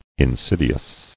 [in·sid·i·ous]